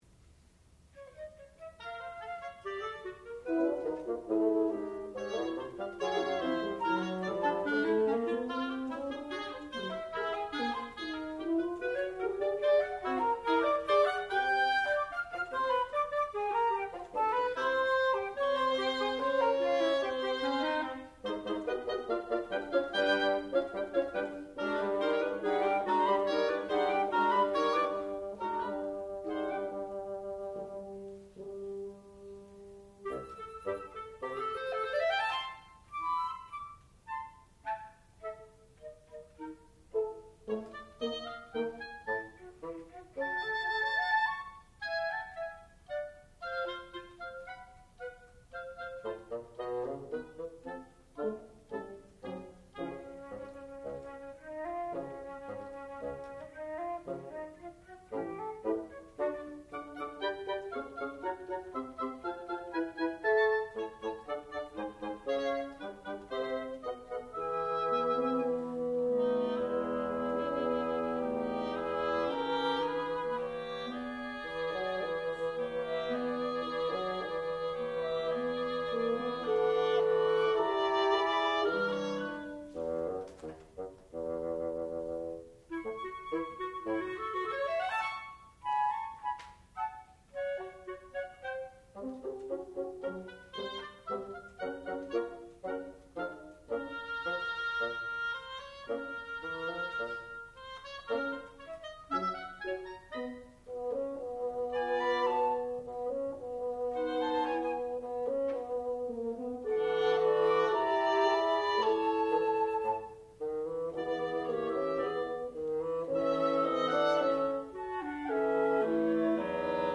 sound recording-musical
classical music
bassoon
horn
flute